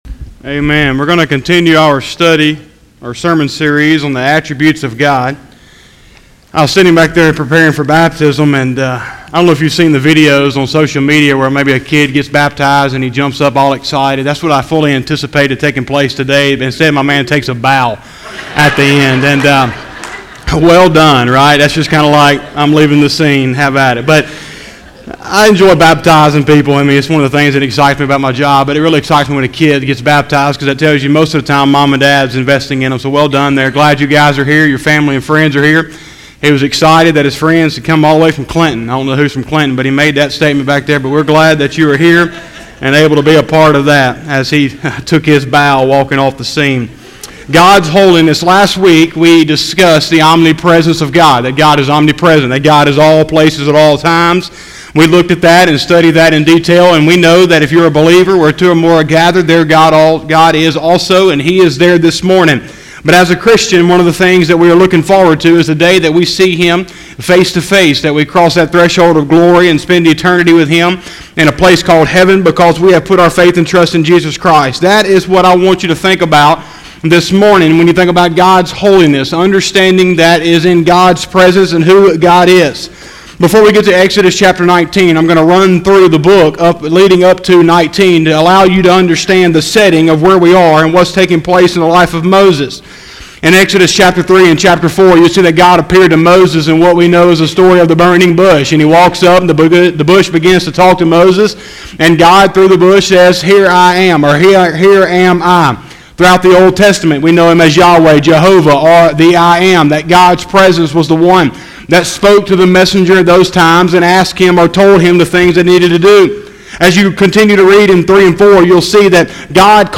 03/08/2020 – Sunday Morning Service